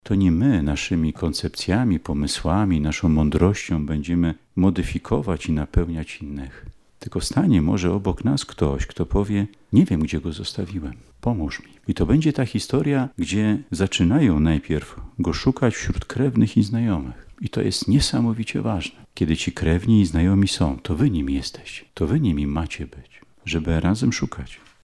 W kaplicy Instytutu Prymasowskiego Stefana Kardynała Wyszyńskiego odbyło się uroczyste posłanie kapłanów i animatorów Ruchu Światło-Życie Diecezji Warszawsko-Praskiej na wakacyjne rekolekcje.
W homilii bp Solarczyk przypomniał, że rekolekcje są przede wszystkim dziełem Boga.